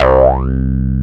FILTR SWP 1P.wav